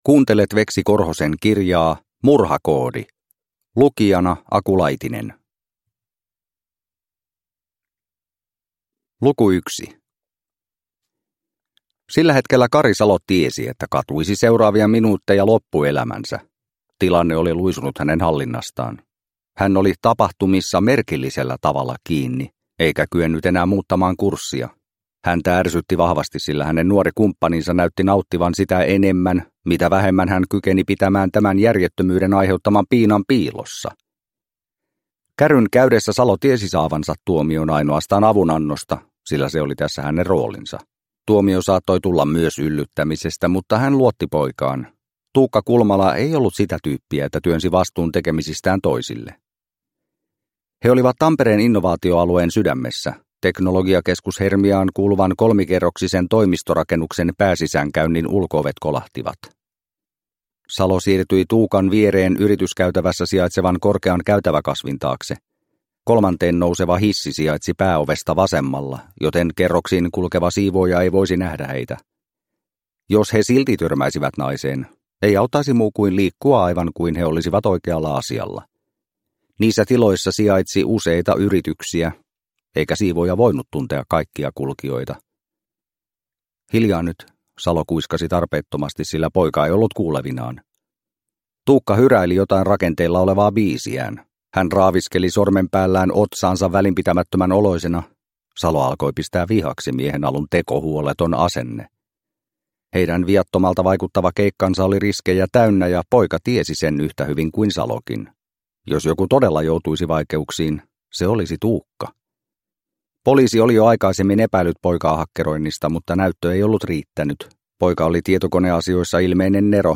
Murhakoodi – Ljudbok – Laddas ner